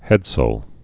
(hĕdsəl, -sāl)